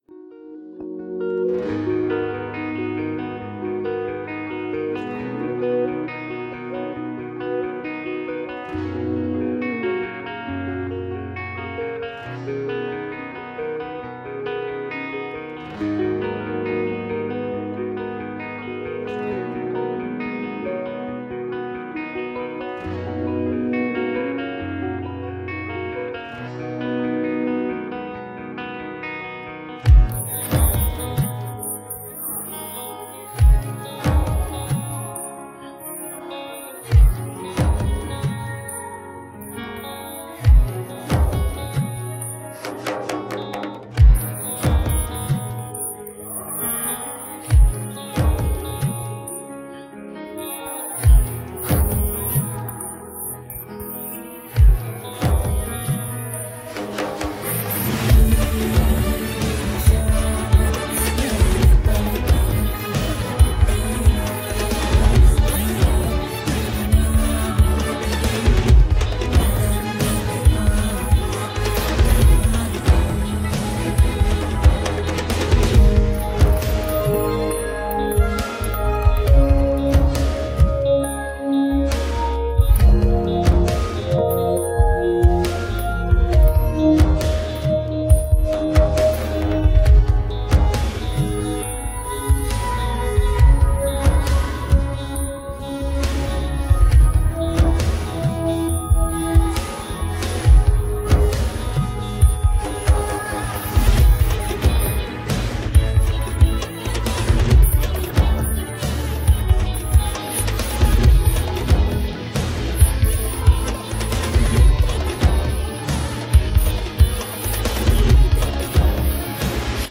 Play Karaoke and Sing wth Us